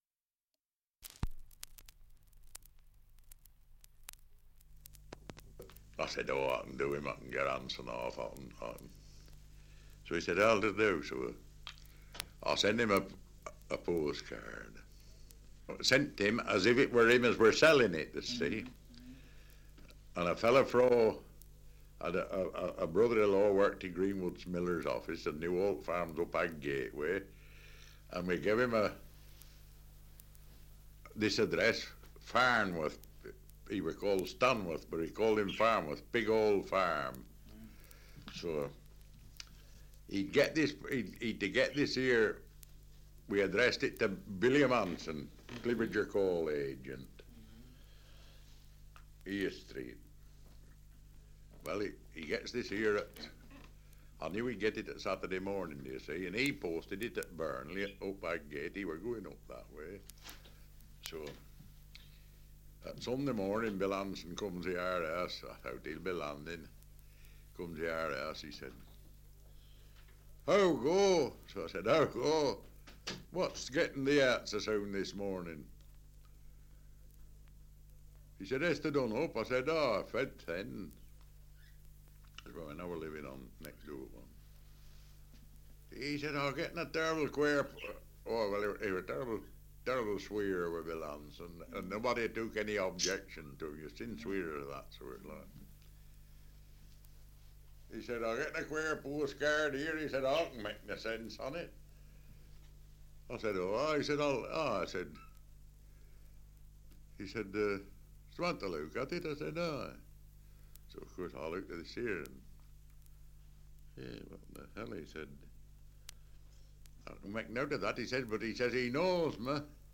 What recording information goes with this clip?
Survey of English Dialects recording in Read, Lancashire 78 r.p.m., cellulose nitrate on aluminium